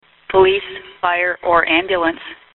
Here is an example of the first thing you will hear when you call 9-1-1:
911 Call 1